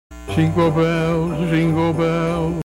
Jingle bell
jingle-bell.mp3